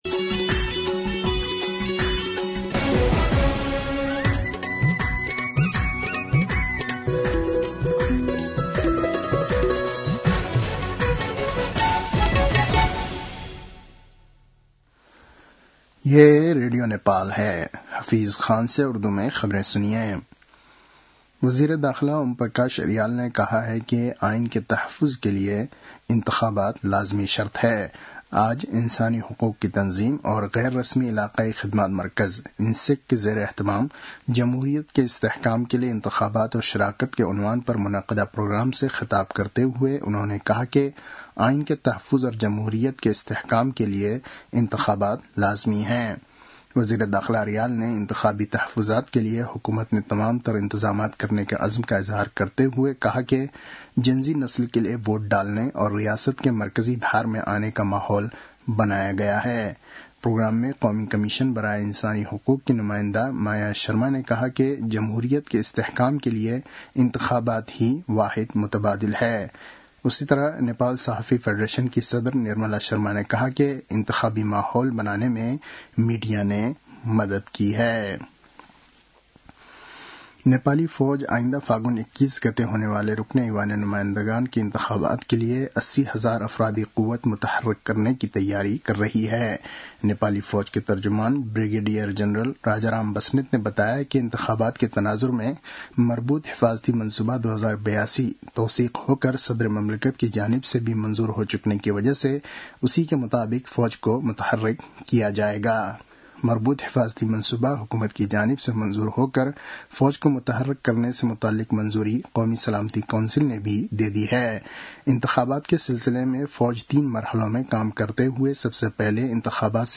उर्दु भाषामा समाचार : २४ पुष , २०८२